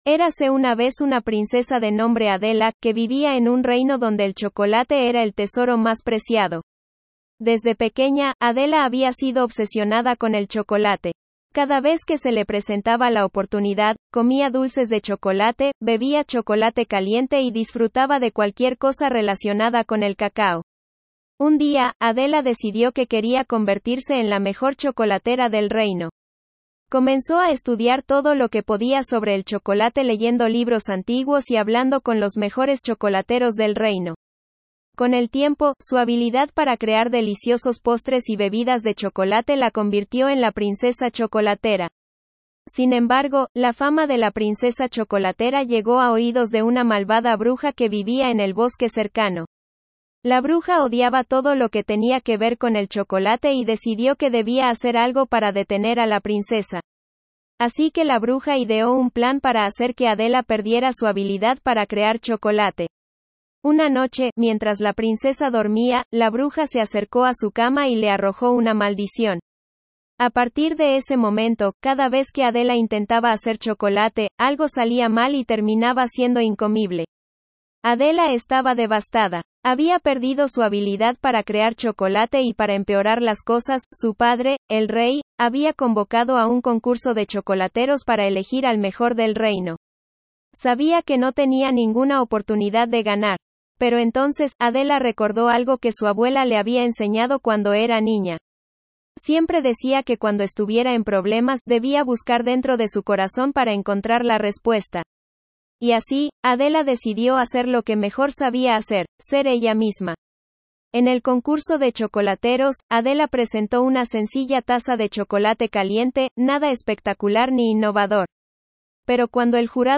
¡Aquí tienes el audio cuento!